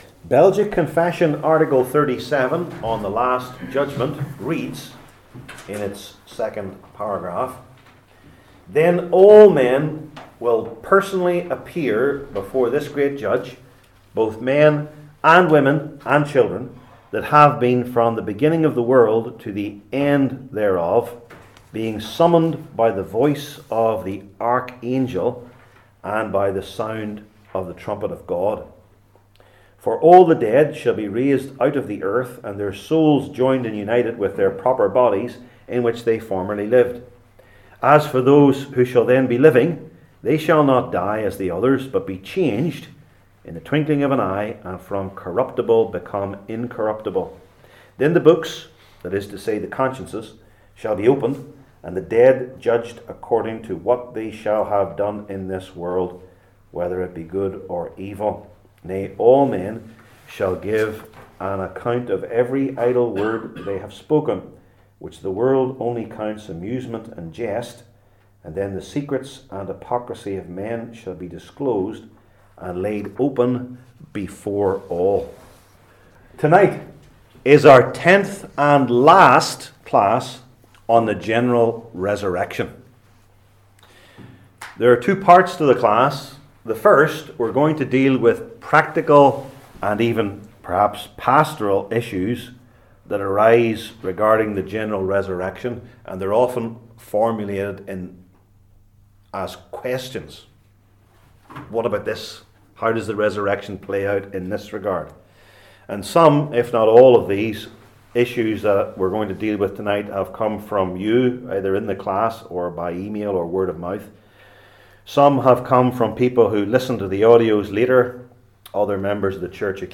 Luke 20:27-40 Service Type: Belgic Confession Classes THE LAST JUDGMENT …